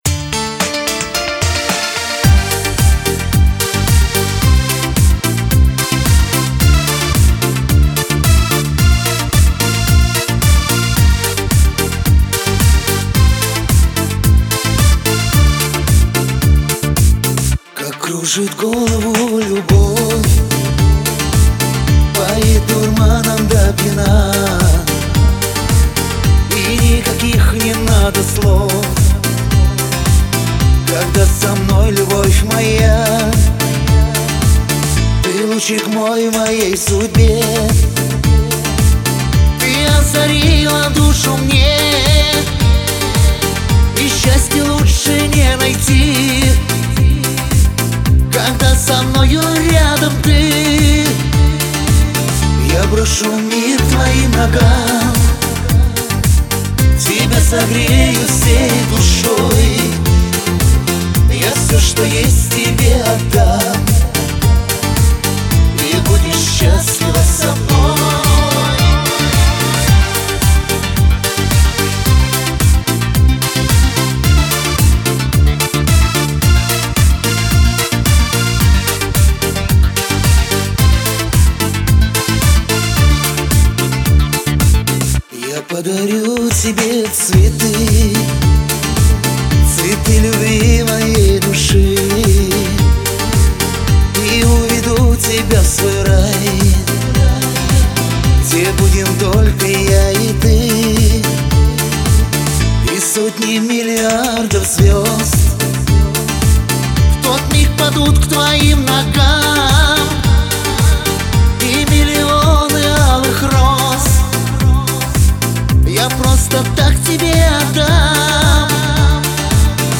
Шансон песни